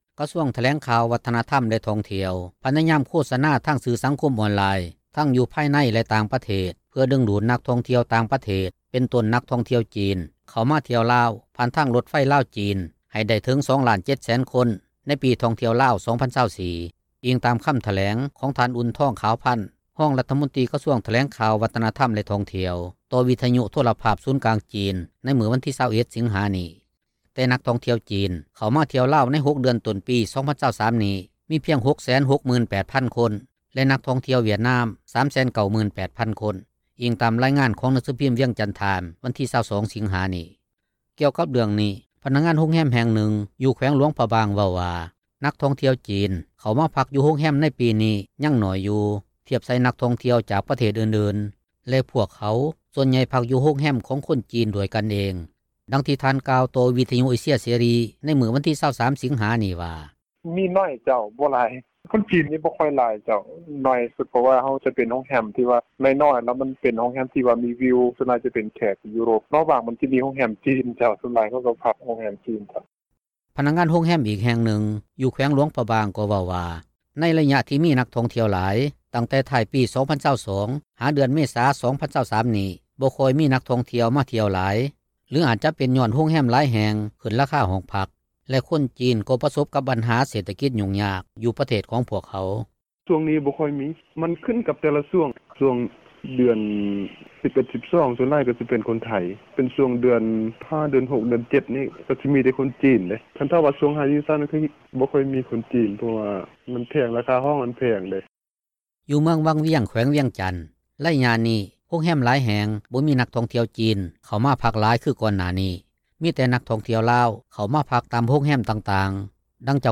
ດັ່ງທີ່ທ່ານກ່າວຕໍ່ ວິທຍຸເອເຊັຽ ເສຣີ ໃນມື້ວັນທີ 23 ສິງຫານີ້ວ່າ:
ດັ່ງເຈົ້າຂອງໂຮງແຮມ ແຫ່ງນຶ່ງຢູ່ເມືອງວັງວຽງເວົ້າວ່າ: